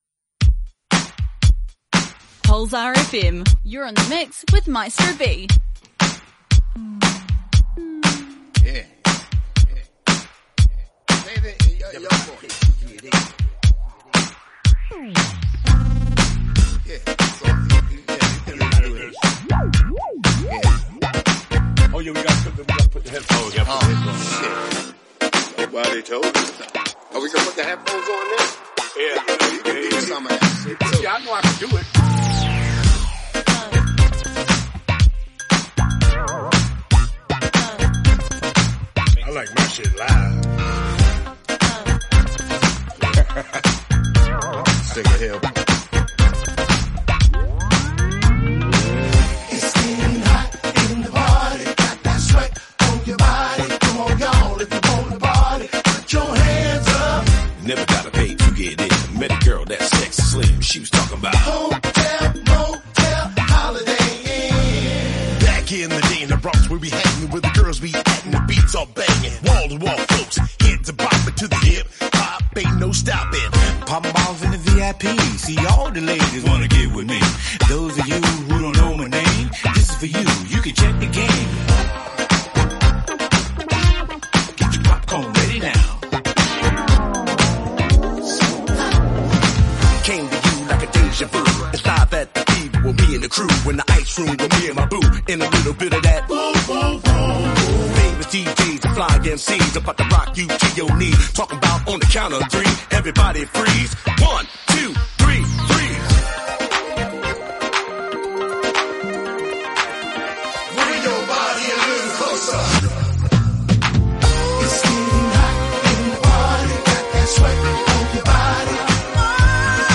a live weekly mixshow
dance music station